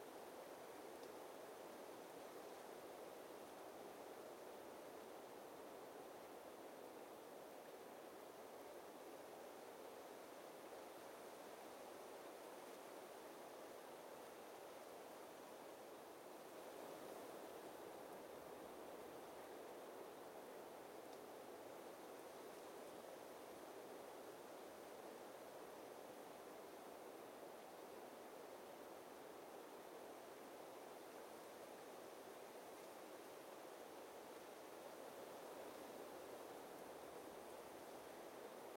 57e0746fe6 Divergent / mods / Soundscape Overhaul / gamedata / sounds / ambient / soundscape / background / houses_1.ogg 1.9 MiB (Stored with Git LFS) Raw History
houses_1.ogg